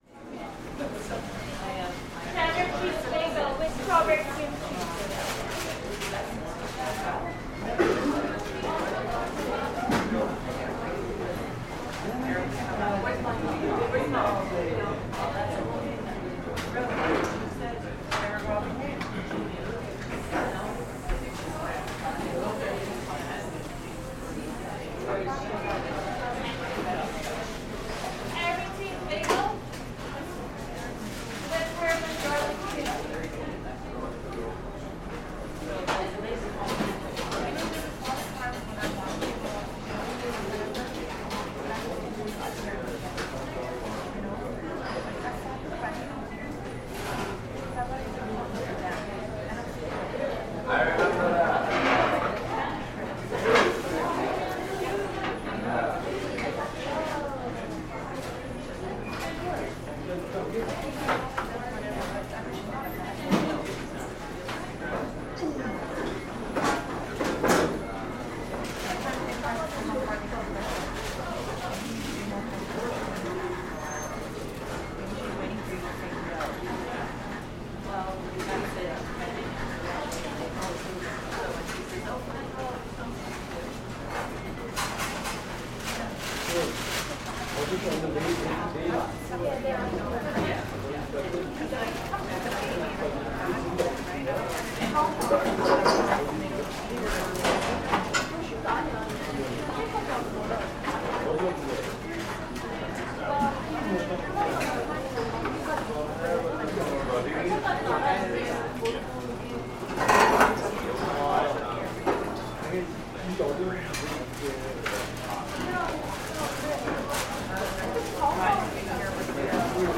Canada – Cafe Ambience, Tim Hortons Coffee Shop, Walla, Vancouver
The calls of baristas and chatter of city dwellers give this clip real texture. You’re placed in the cacophony of a bustling metropolis filling up on caffeine for the day.
Junglesonic-Canada-Cafe-Ambience-Tim-Hortons-Coffee-Shop-Walla-Vancouver.mp3